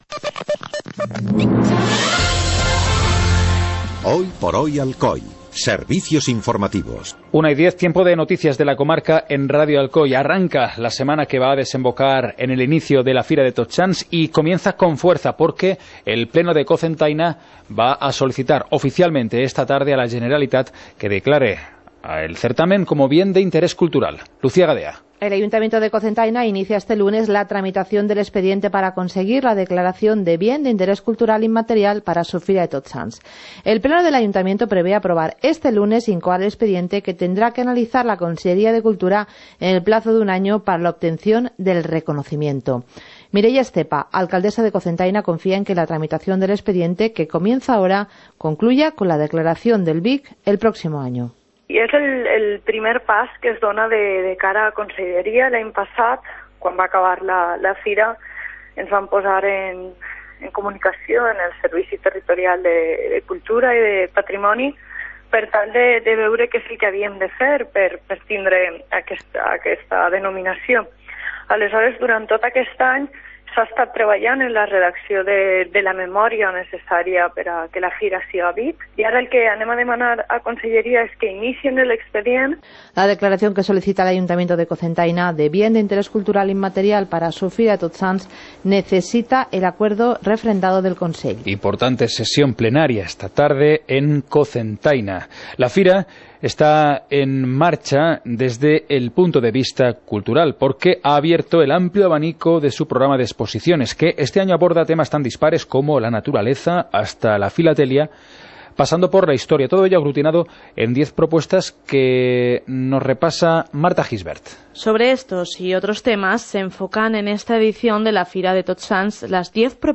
Informativo comarcal - lunes, 24 de octubre de 2016